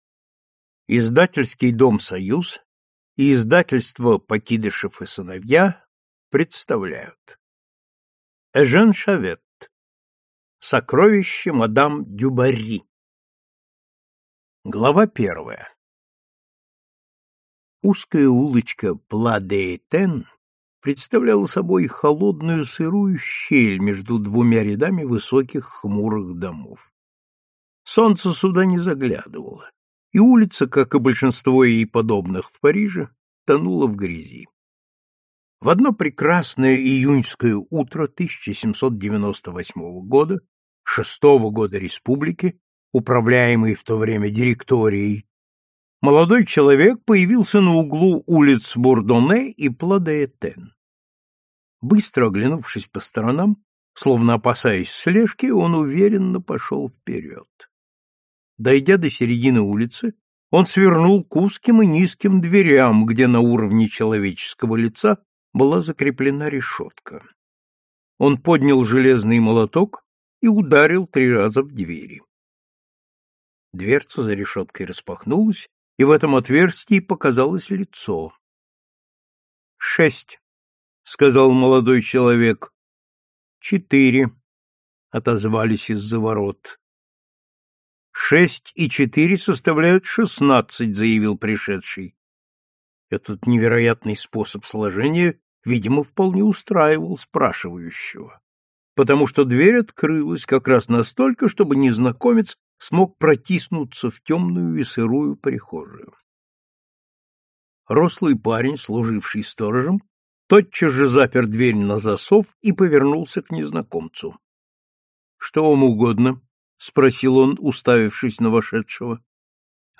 Аудиокнига Сокровище мадам Дюбарри | Библиотека аудиокниг